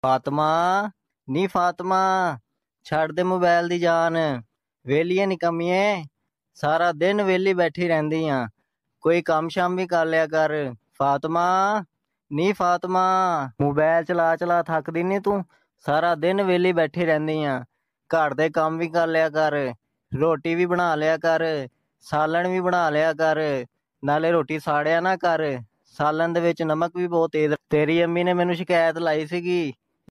Goat 🐐 Calling Funny Name Sound Effects Free Download